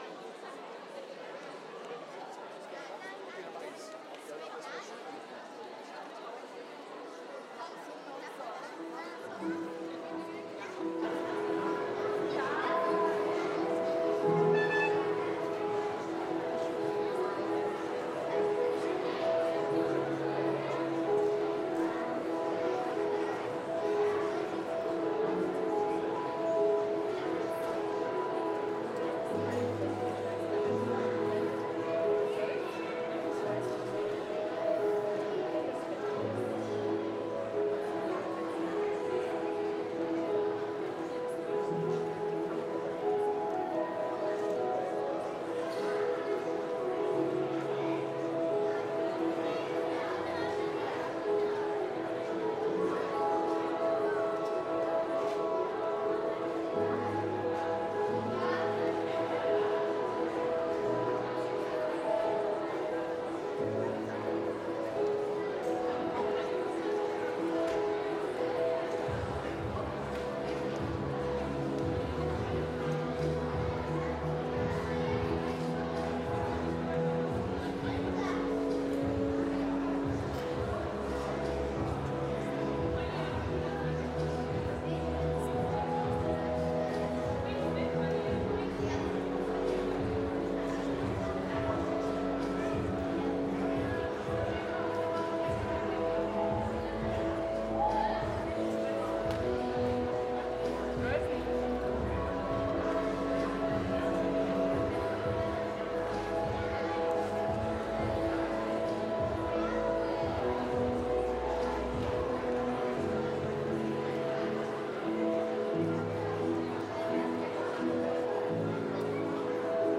Mitschnitt vom 23.08.2025 zum Thema „Kinder" von Kinder